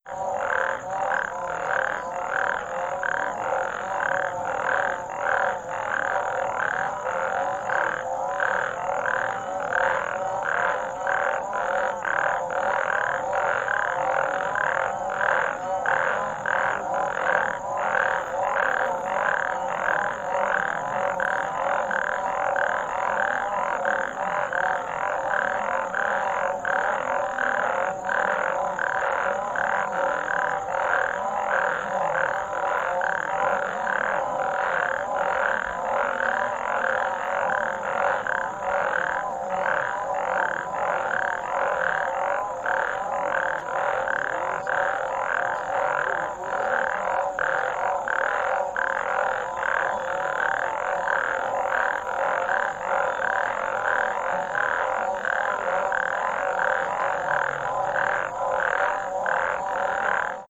• toads and frogs singing in the night ambient.wav
toads_and_frogs_singing_in_the_night_ambient_53d.wav